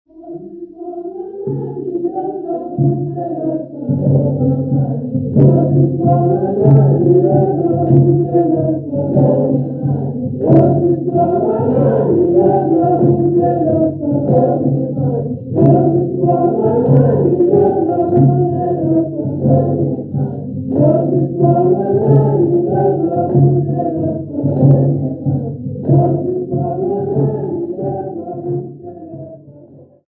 Siswati church music workshop participants
Folk music
Sacred music
Field recordings
Siswati church music workshop performance, accompanied by the drum.
96000Hz 24Bit Stereo